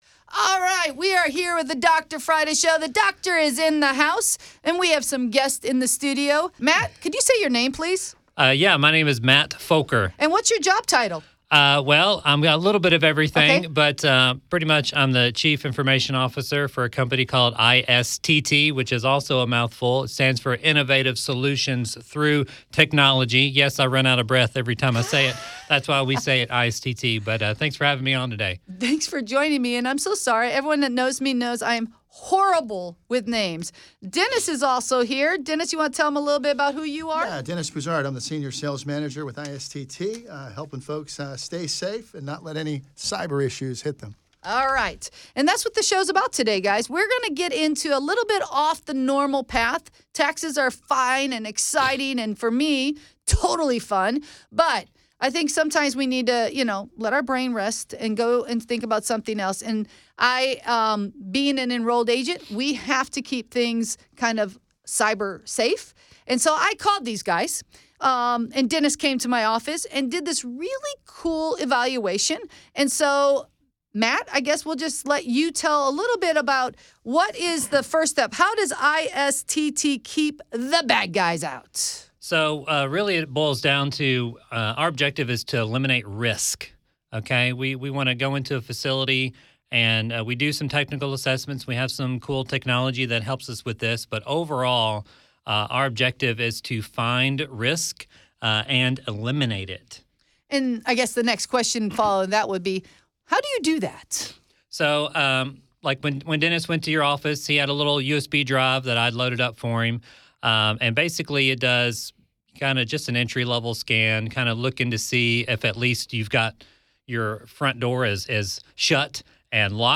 The Doctor is in the house, and we have some guests in the studio.